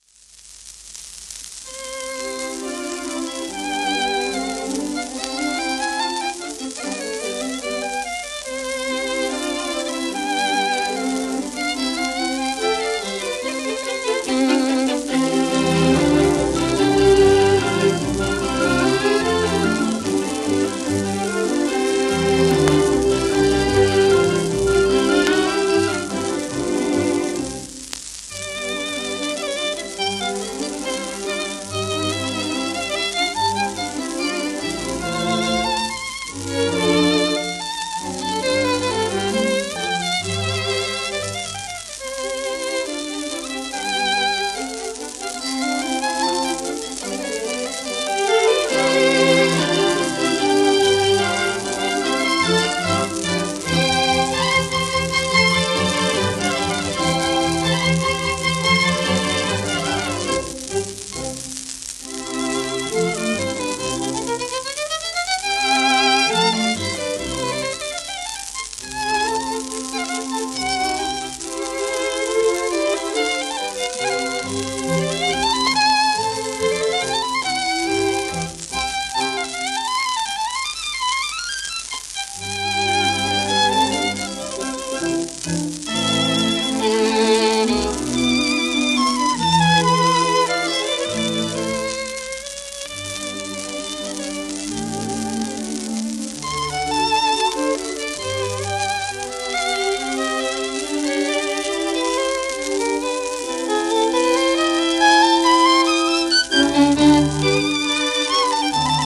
ジャン・プーニエ(Vn:1907-68)
w/W.ゲール(cond),シンフォニー・オーケストラ
ヴァイオリンと管弦楽の為のロンド ハ長調K.373（モーツァルト）
盤質A- *薄い面擦れ,音ok
シェルマン アートワークスのSPレコード